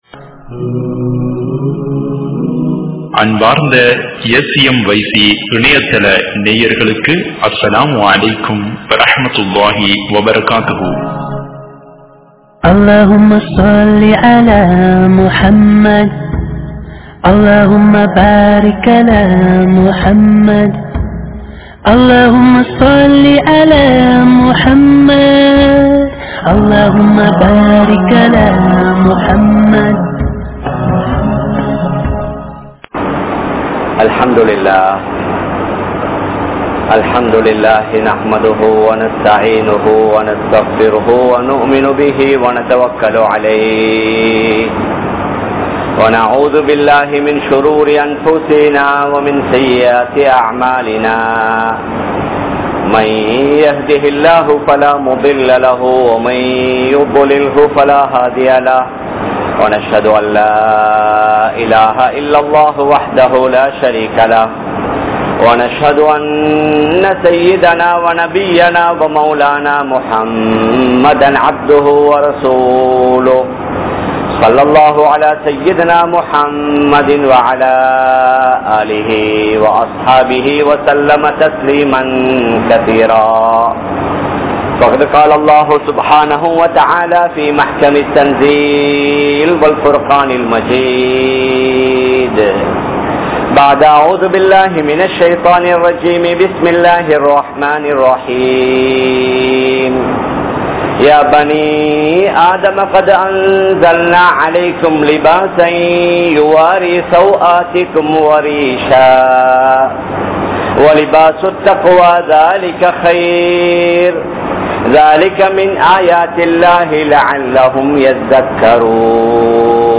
Aadai Aninthum Nirvaanam (ஆடை அணிந்தும் நிர்வாணம்) | Audio Bayans | All Ceylon Muslim Youth Community | Addalaichenai
Kollupitty Jumua Masjith